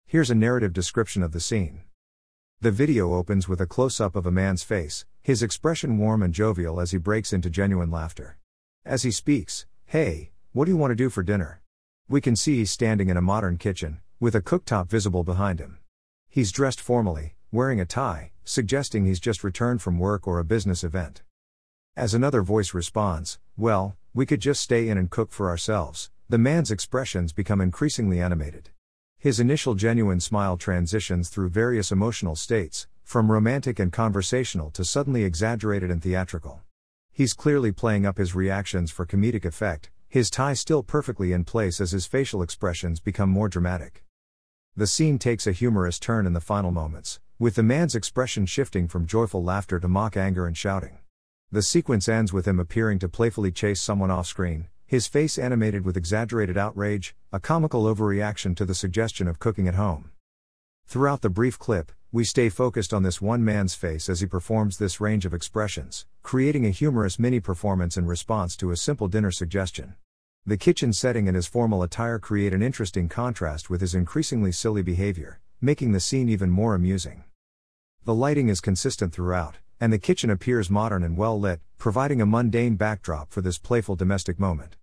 3. Audio Description
❌ Missing visual context   ✅ Natural voice narration